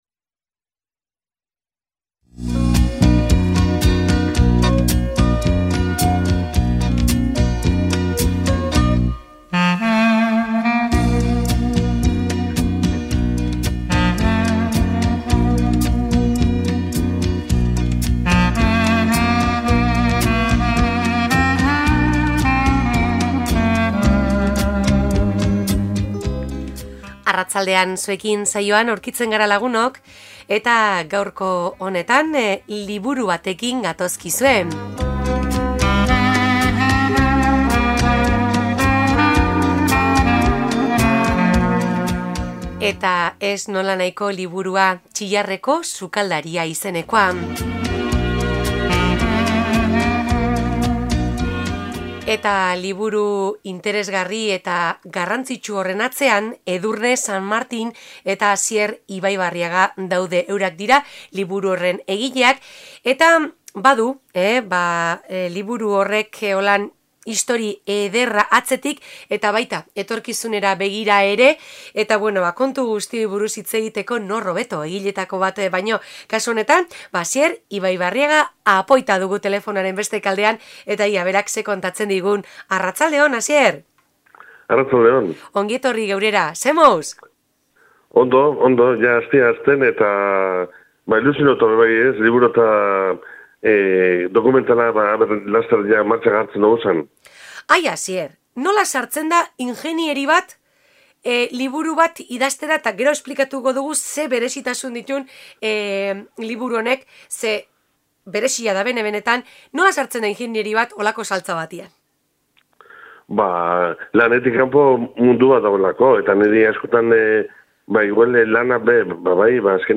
Alkarrizketa Txillarreko sukaldea 25-10-06 Reproducir episodio Pausar episodio Mute/Unmute Episode Rebobinar 10 segundos 1x Fast Forward 30 seconds 00:00 / 23:31 Suscribir Compartir Feed RSS Compartir Enlace Incrustar